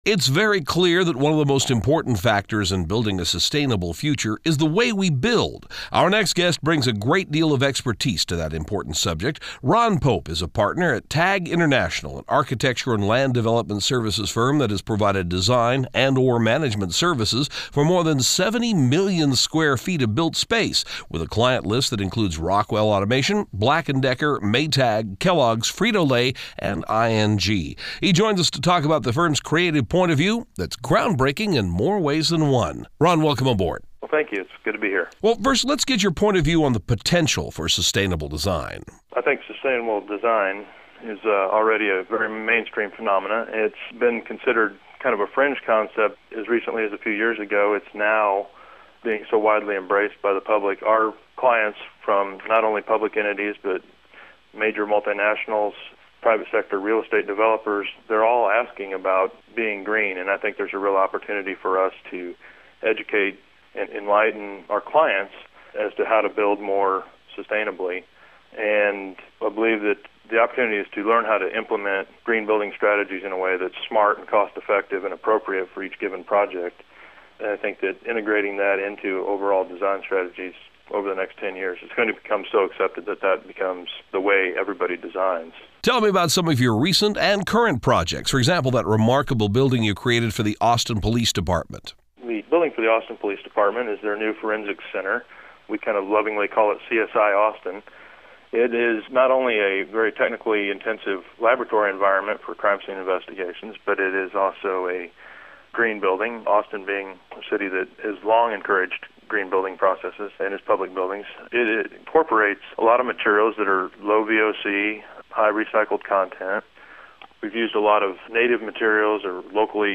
Sustainable Design: Sky Radio Interview